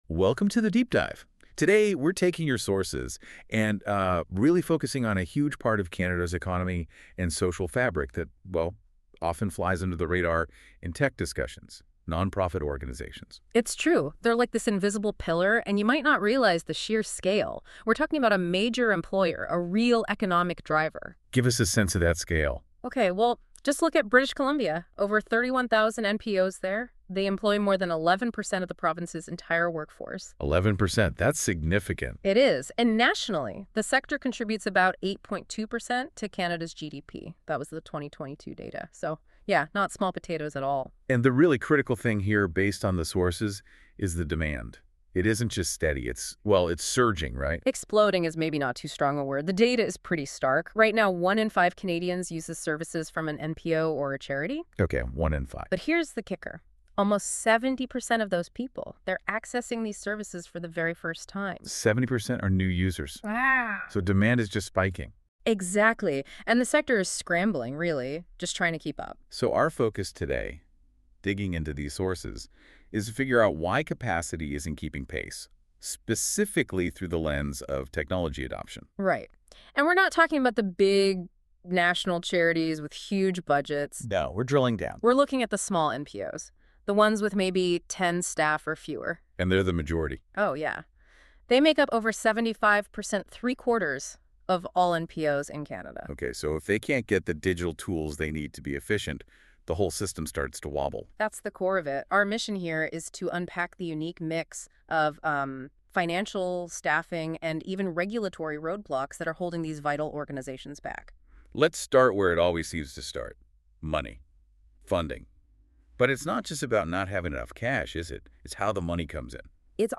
The paper The podcast (created by AI, ~15 min.)